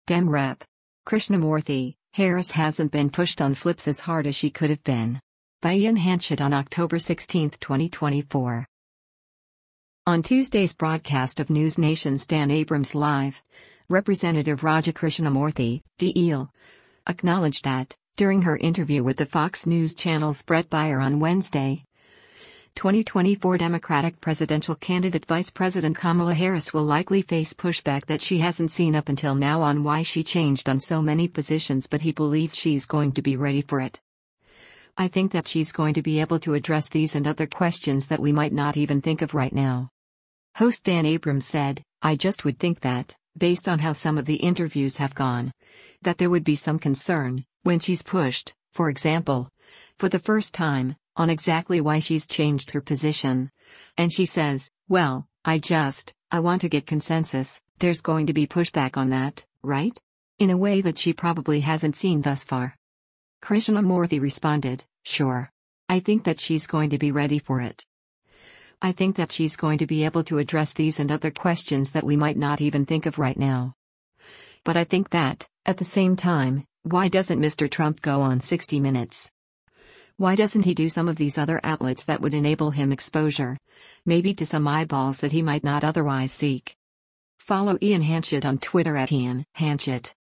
On Tuesday’s broadcast of NewsNation’s “Dan Abrams Live,” Rep. Raja Krishnamoorthi (D-IL) acknowledged that, during her interview with the Fox News Channel’s Bret Baier on Wednesday, 2024 Democratic presidential candidate Vice President Kamala Harris will likely face pushback that she hasn’t seen up until now on why she changed on so many positions but he believes “she’s going to be ready for it.